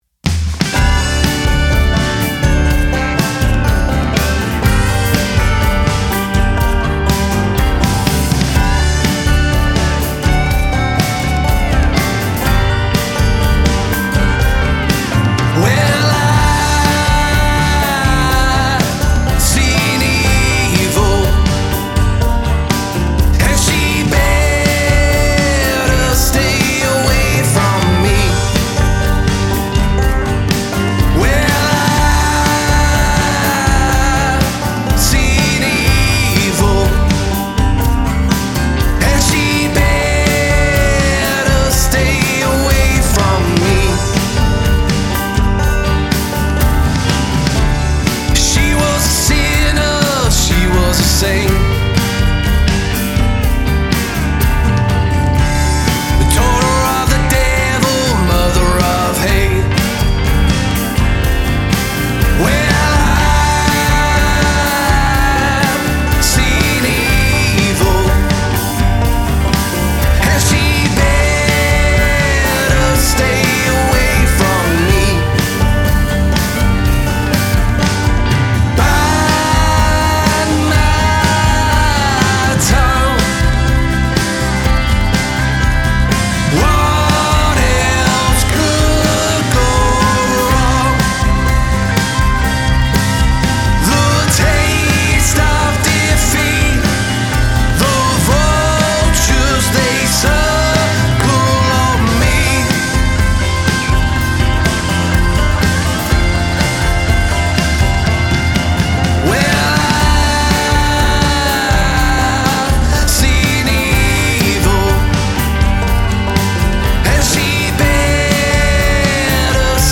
Male Vocal, Guitar, Keys, Lap Steel, Bass Guitar, Drums